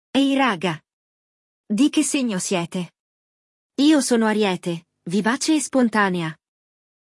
Num bom barzinho, 4 amigos conversam sobre um assunto que apesar de dividir opiniões sempre gera um bom papo.
IL DIALOGO – O DIÁLOGO